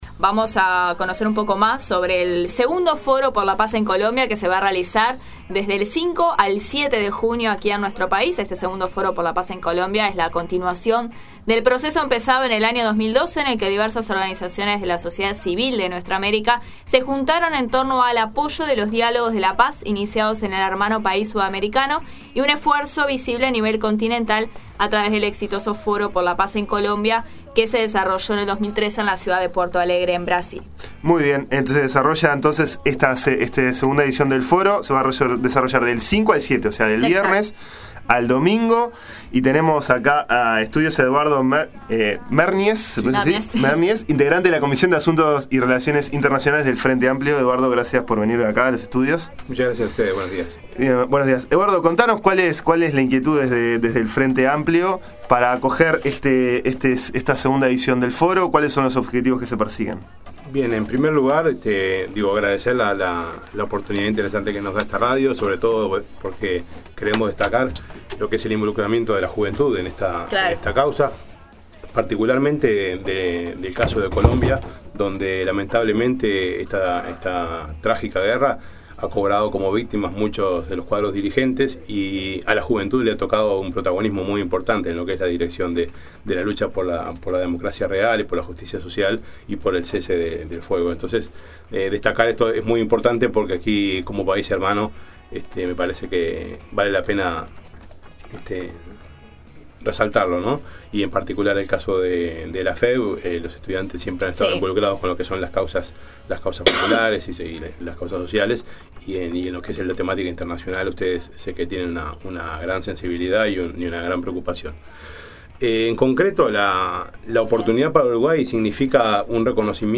II Foro por la Paz en Colombia, entrevistas